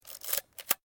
Photo_instamatic.ogg